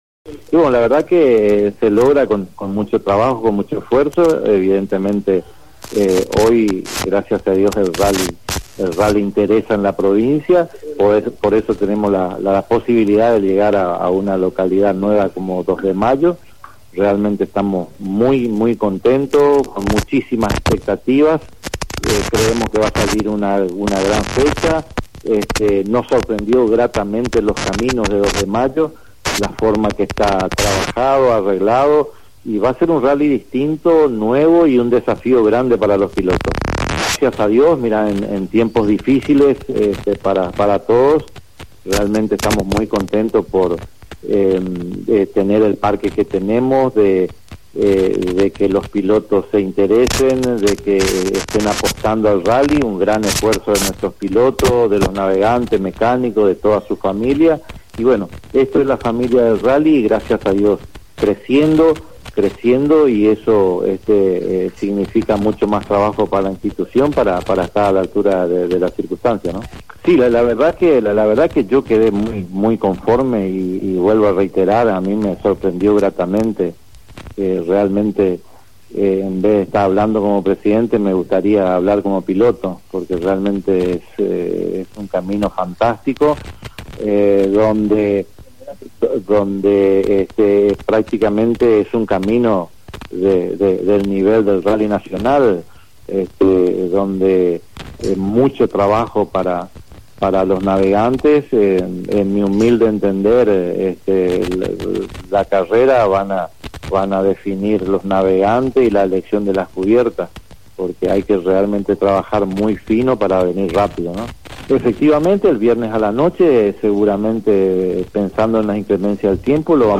en dialogo con La Radio manifestó